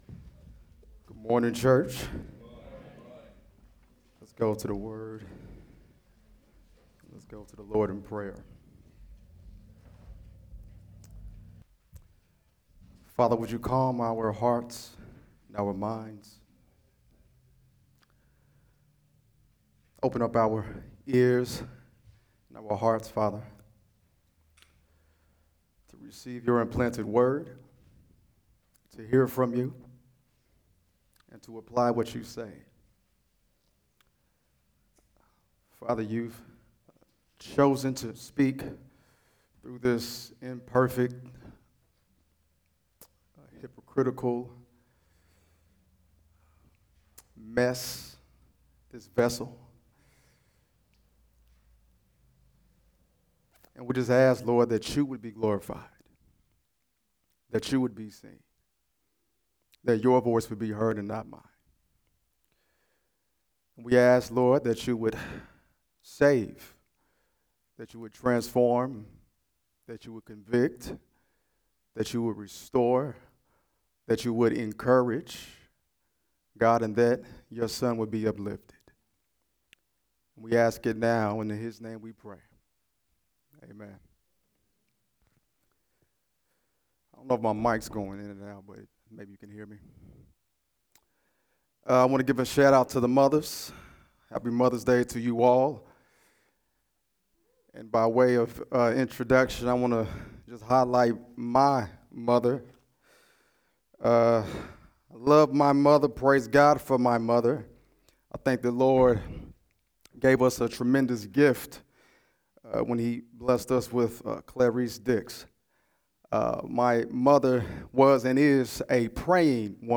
sunday-sermon-5-11-25.mp3